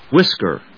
音節whis・ker 発音記号・読み方
/(h)wískɚ(米国英語), wískə(英国英語)/